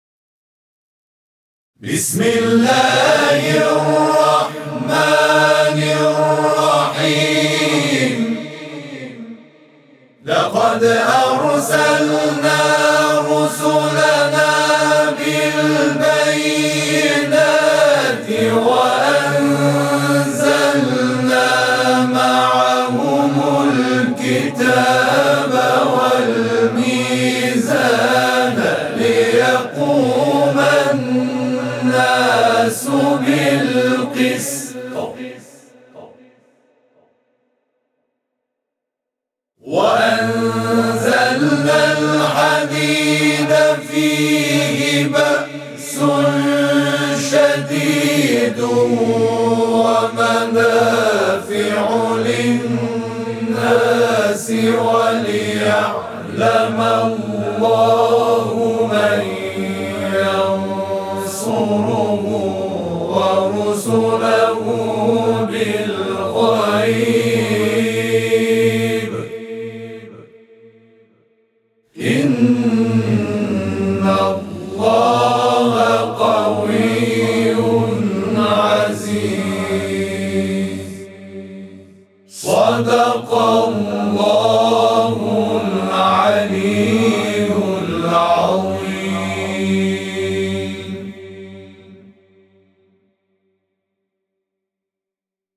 صوت همخوانی آیه 25 سوره حدید از سوی گروه تواشیح «محمد رسول‌الله(ص)»